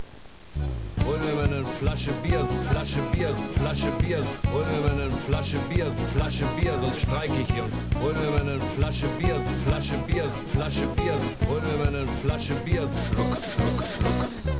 was für's Ohr (315KB) klingelnde Kassen